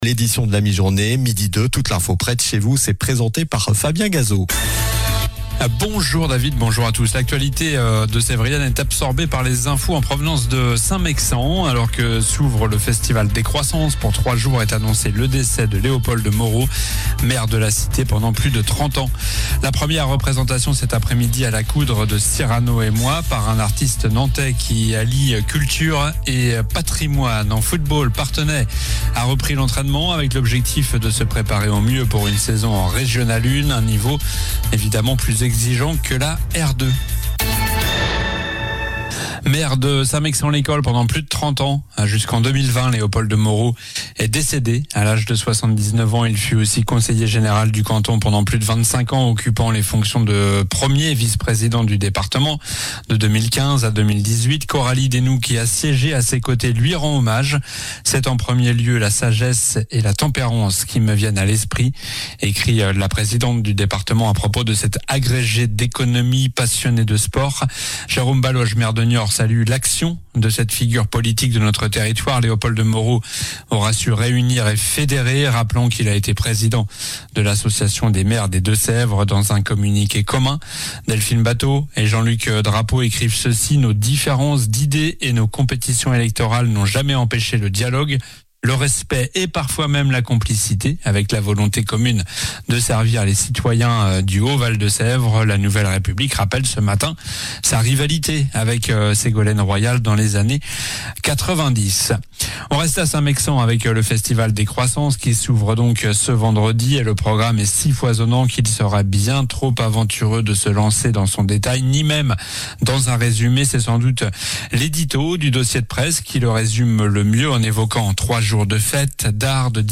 Journal du vendredi 25 juillet (midi)